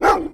bark2.wav